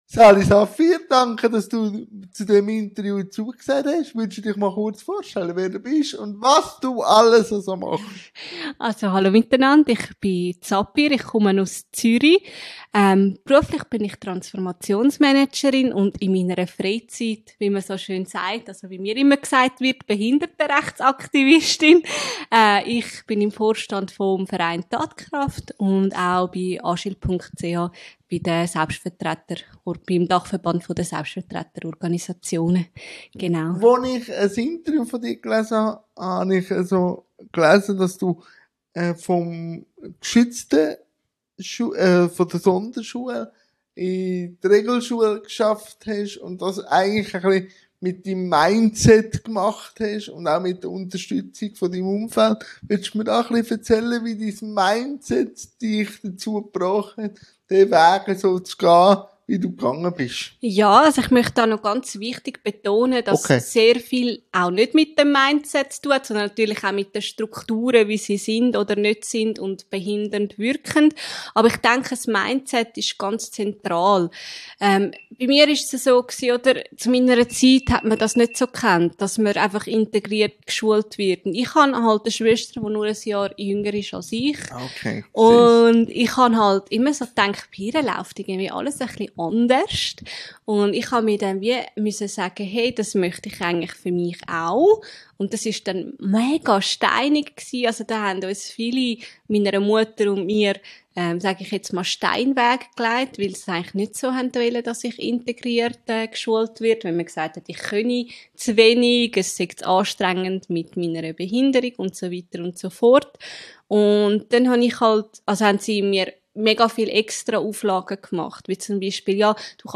INTERVIEW-THEMEN | Inklusion, Bildung u. v. m Ich danke meinem Gast und wünsche viel Spass beim Interview